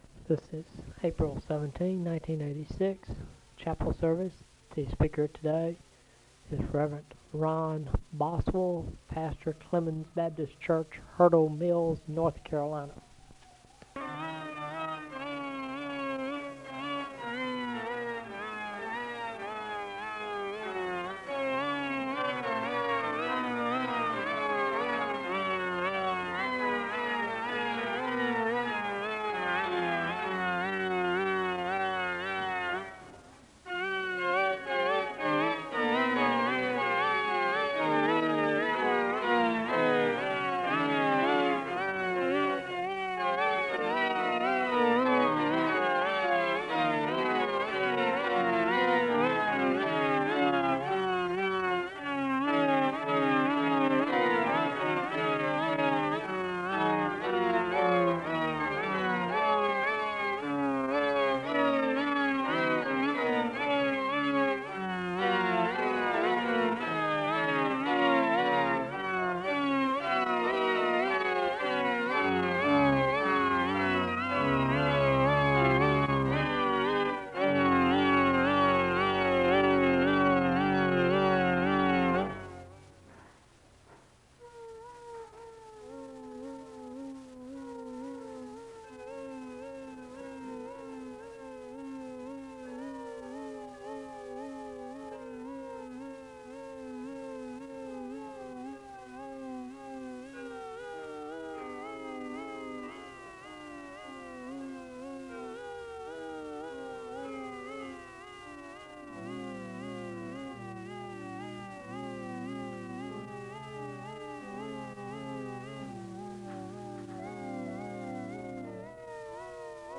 The service begins with organ music (0:00-4:38). There is a call to worship (4:39-5:05). There is a moment of prayer (5:06-6:14).
There is a Scripture reading from Isaiah 53, Hebrews 4, and John 11 (7:13-10:09). The choir sings a song of worship (10:10-13:56).